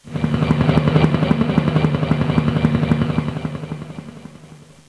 Helicopter.snd